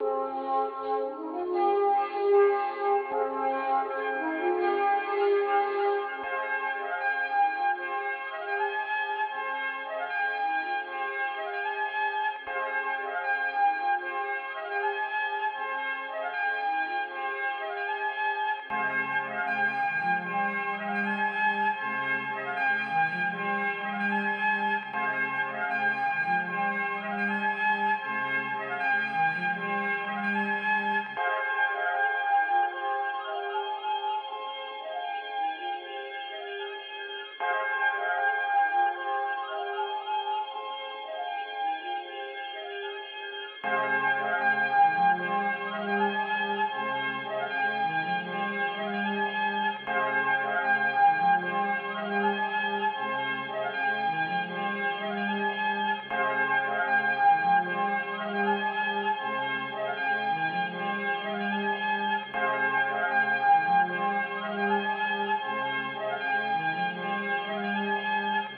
Teck-mask on_77bpm.wav